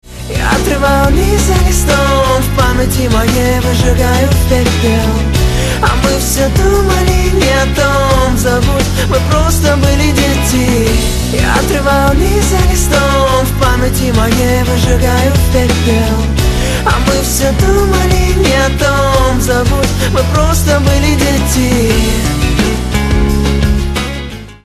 • Качество: 128, Stereo
поп
красивый мужской голос